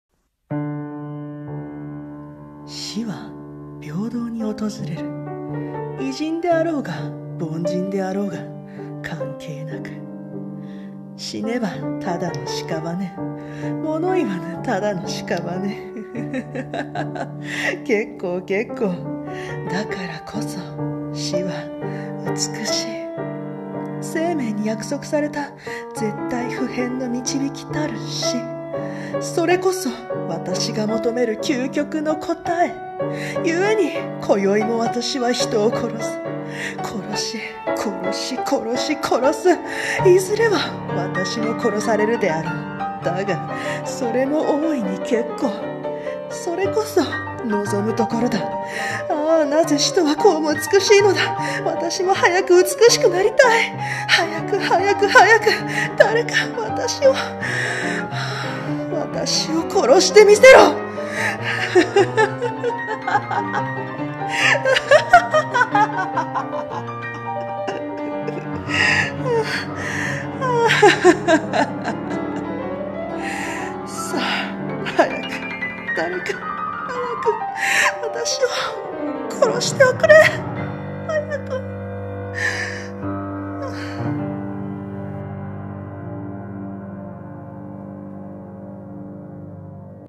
【声劇】題 : 殺人鬼